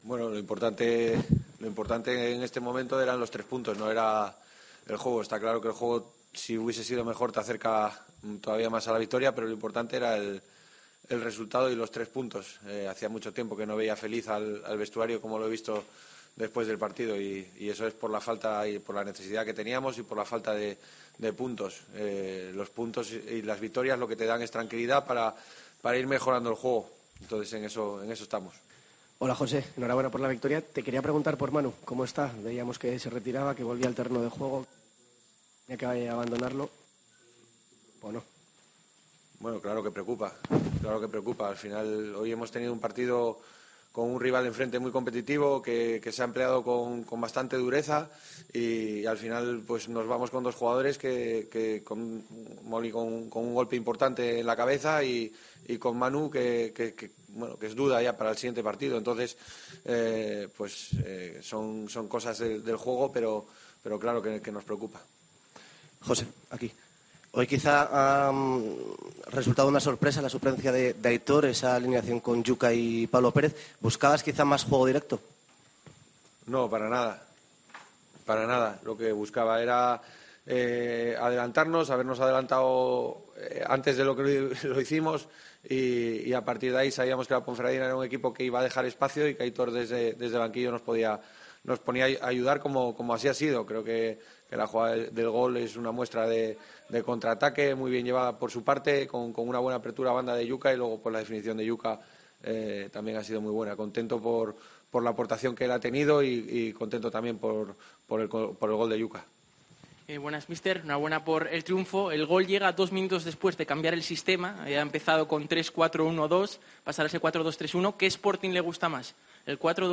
POSTPARTIDO